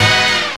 JAZZ STAB 28.wav